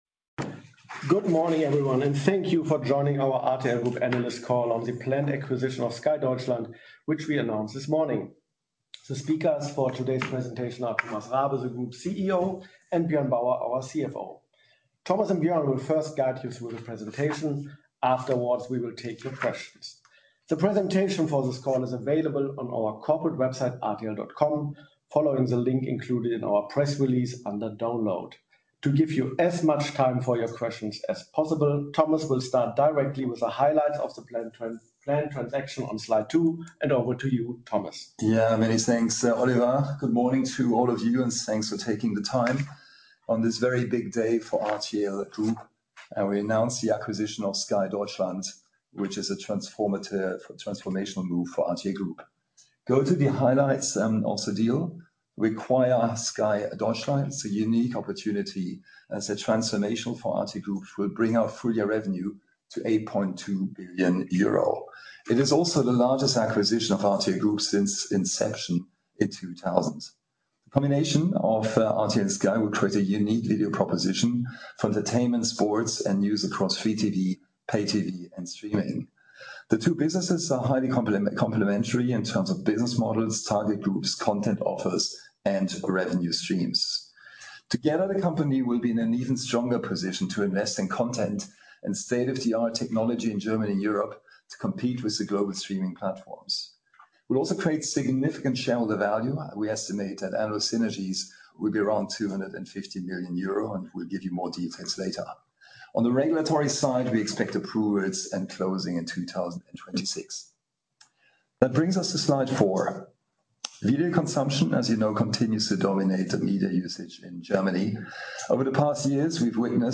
Recording analyst call (MP3)